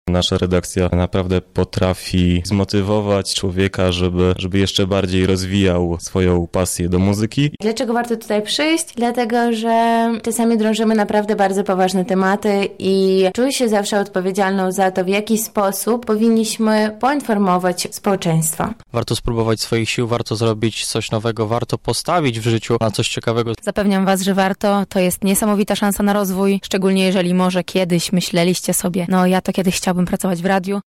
Dlaczego warto do nas dołączyć, mówią nasi koledzy: